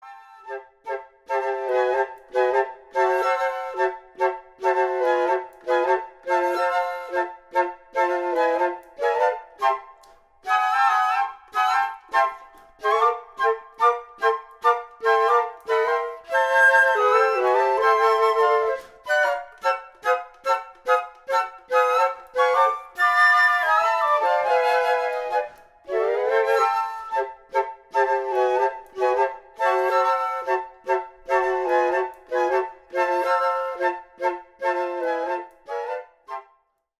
Pour quatuor de flûtes